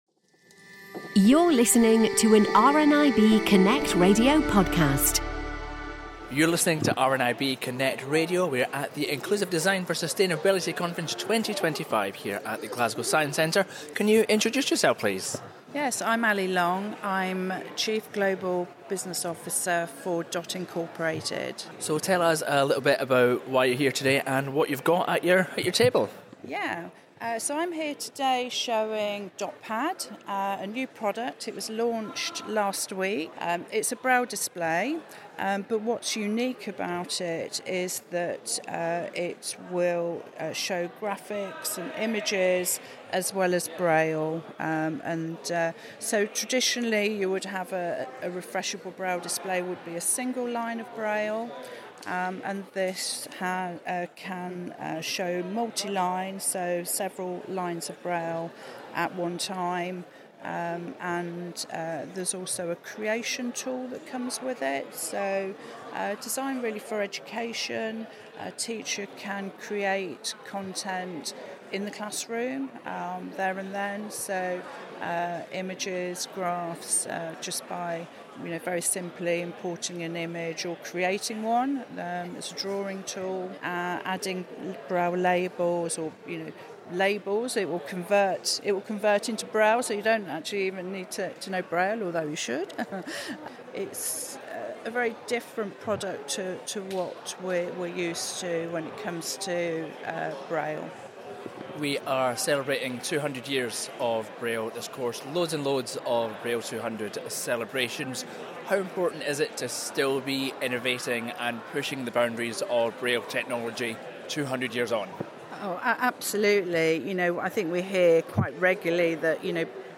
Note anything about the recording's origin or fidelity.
More new from IDS Conference